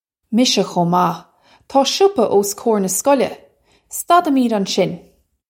Misha khoh mah. Taw shuppa ose ko-ir nah skoll-eh. Stad-he-midg on-shin.
This is an approximate phonetic pronunciation of the phrase.